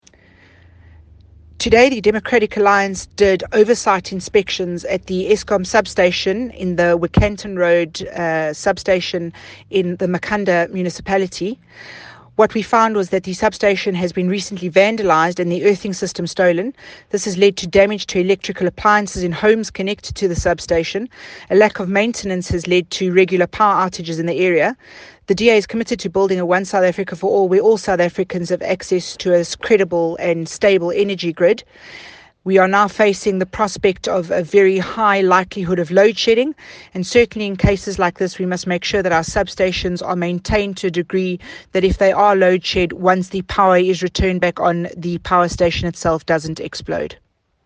soundbite by DA Team One South Africa Spokesperson for State Capture, Natasha Mazzone